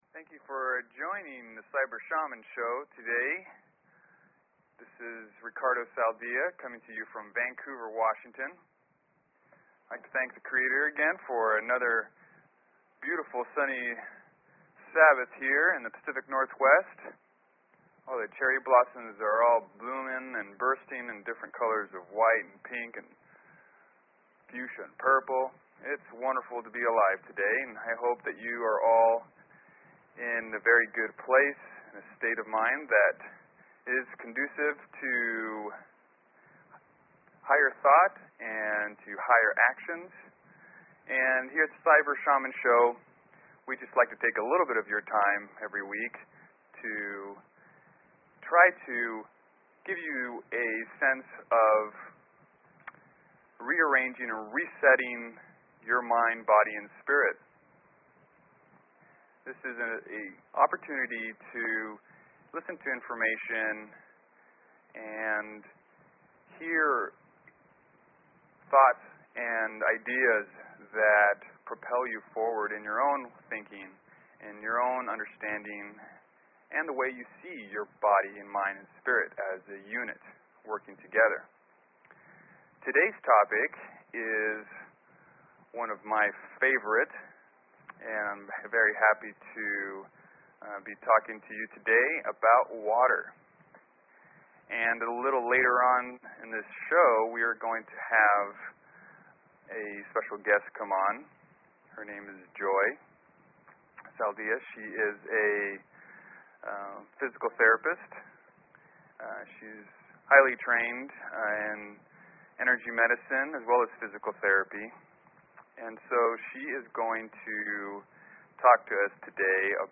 Talk Show Episode, Audio Podcast, Cyber_Shaman and Courtesy of BBS Radio on , show guests , about , categorized as